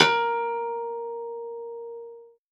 53v-pno06-A2.aif